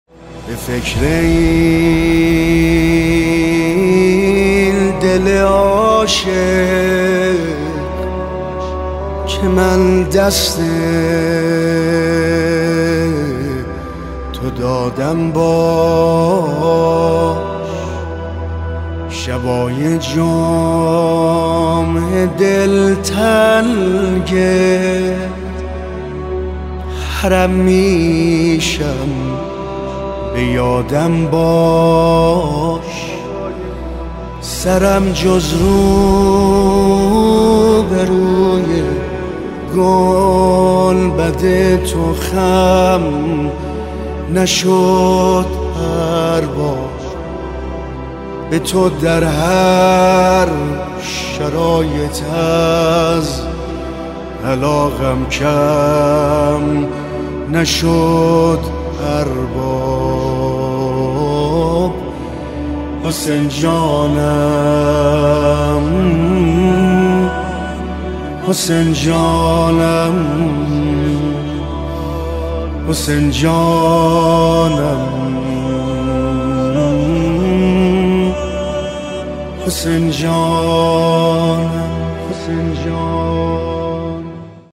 عبد الرضا هلالی | شهادت امام صادق (ع) | هیئت مکتب الشهدا قائم شهر 26خرداد 99 | پلان 3